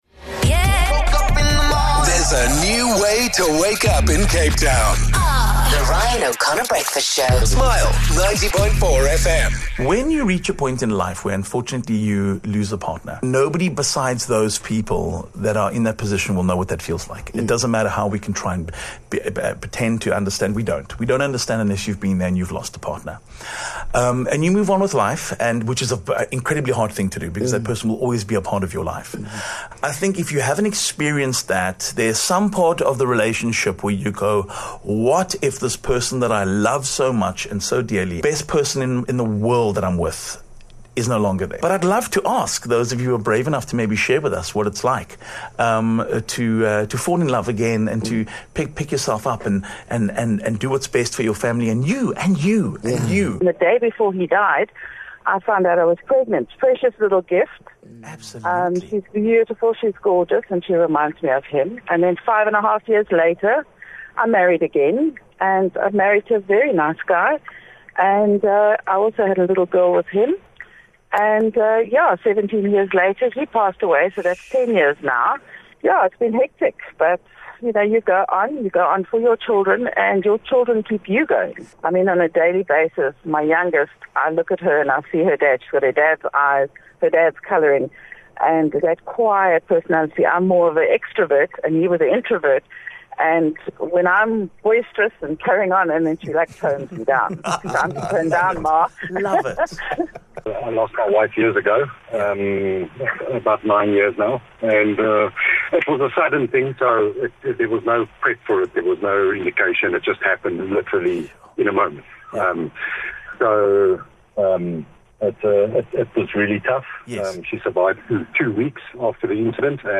10 Jun Losing a partner and moving on: listeners share their stories